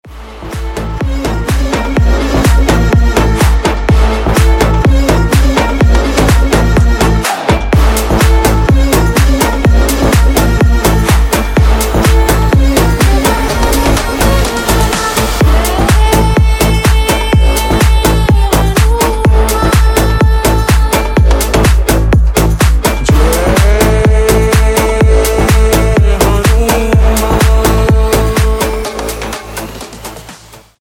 Клубные Рингтоны » # Восточные Рингтоны
Танцевальные Рингтоны